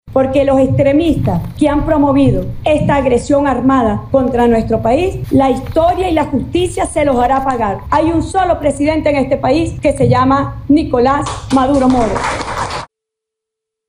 La respuesta no tardó: Delcy Rodríguez —hasta ahora vicepresidenta y quien jurará como presidenta interina— condenó la intervención militar estadounidense y reivindicó a Maduro.